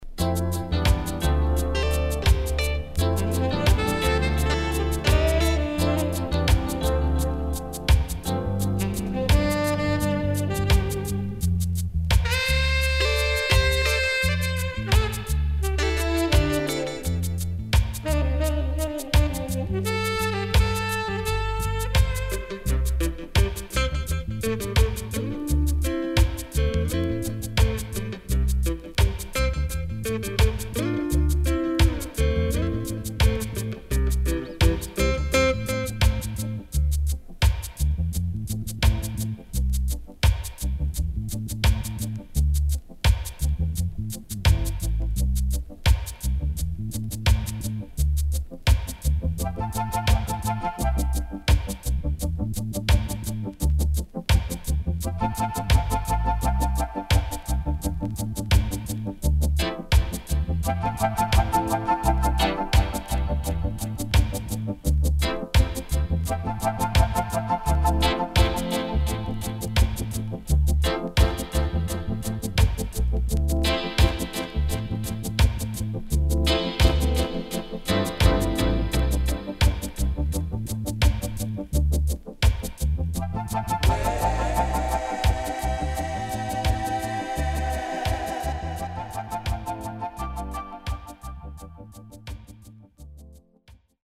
CONDITION SIDE A:VG+〜EX-
Sweet Vocal & Inst.W-Side Good
SIDE A:少しチリノイズ入りますが良好です。